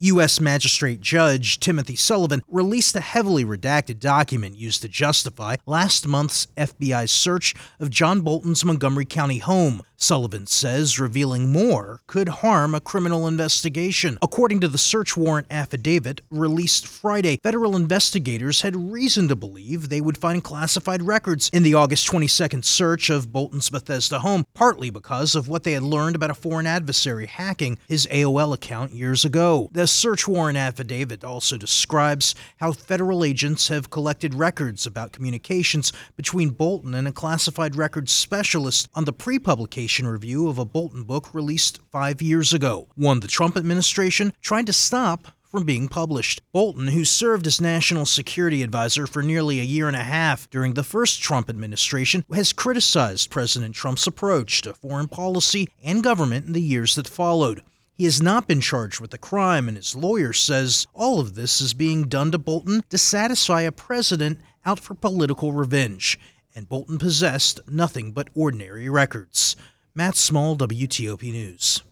Live Radio